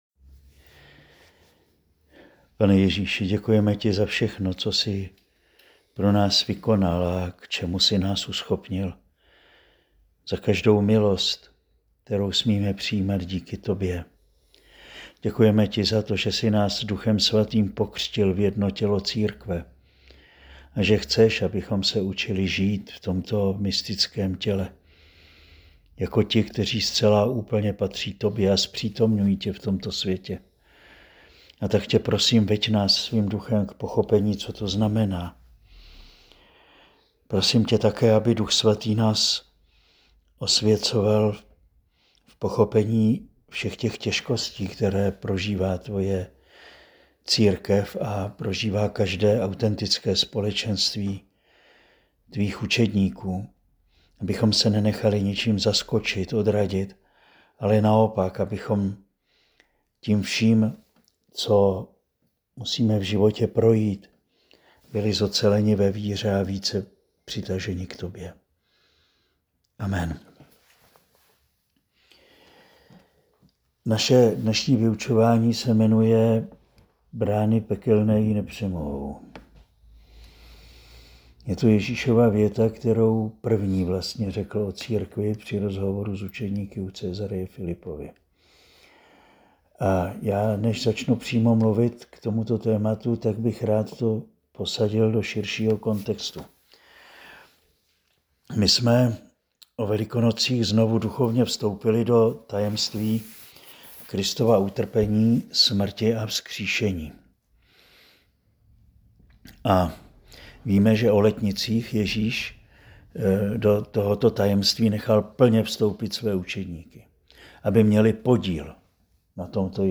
Přednáška zazněla dne 3. 5. 2025